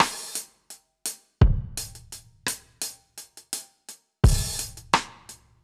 Db_DrumsA_Dry_85-01.wav